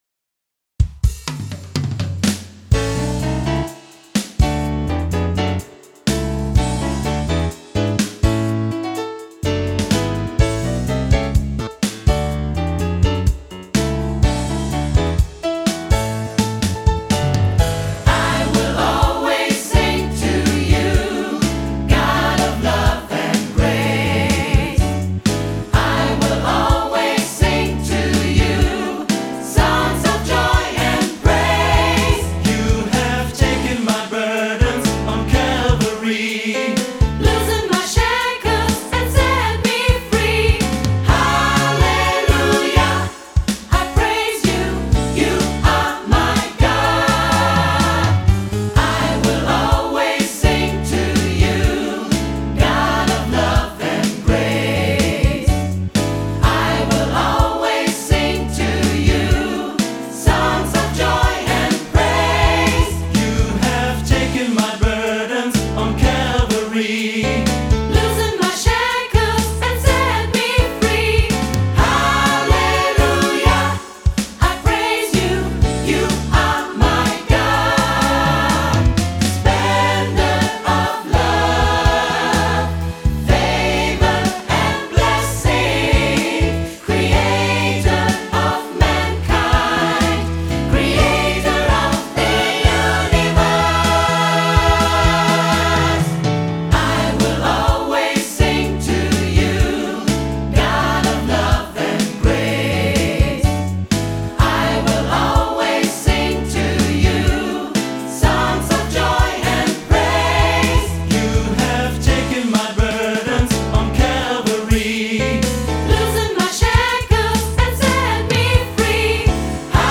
• SAB + Piano